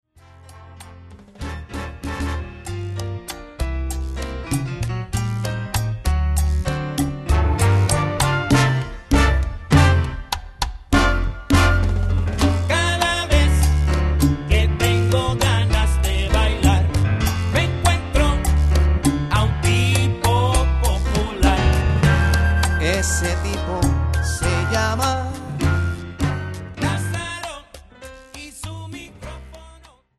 Category: salsa
Style: guajira
Solos: vocal
Featured Instrument: vocal